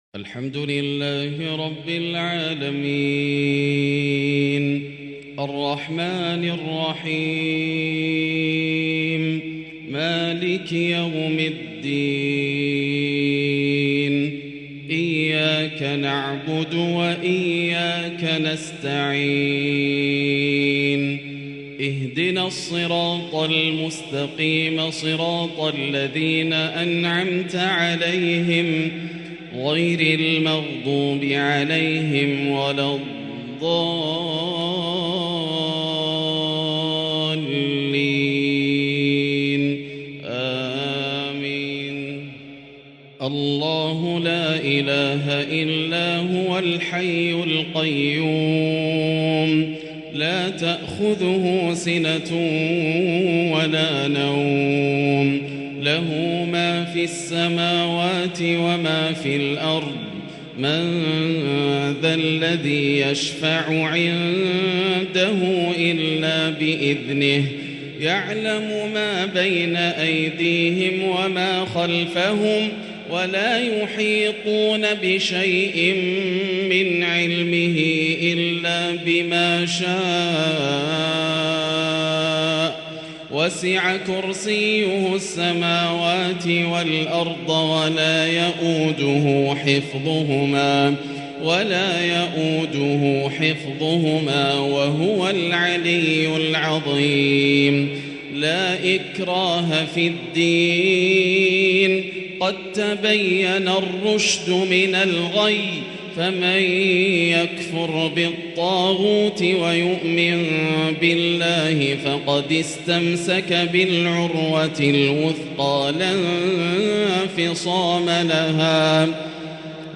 تلاوات عجيبة بتحبير مميز للشيخ د.ياسر الدوسري من المسجد الحرام 1444هـ > تلاوات عام 1444هـ > مزامير الفرقان > المزيد - تلاوات الحرمين